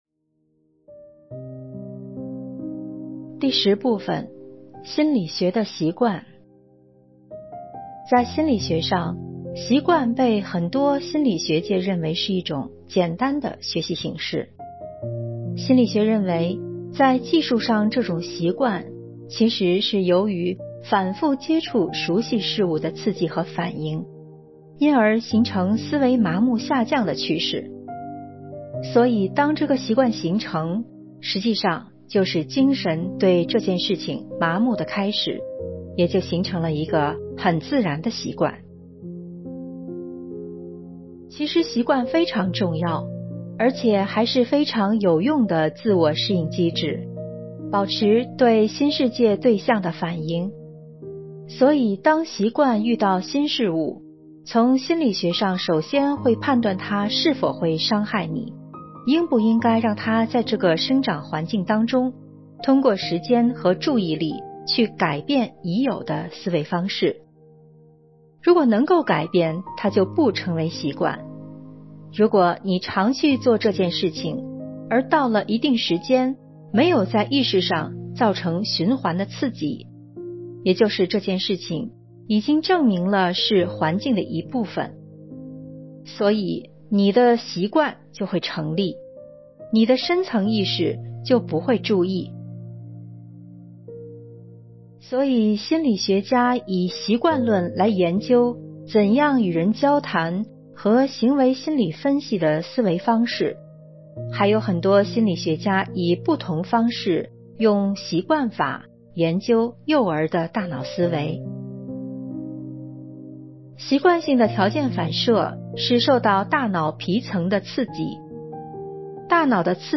10. 心理学的习惯！《论心理学的现代概念》【有声书】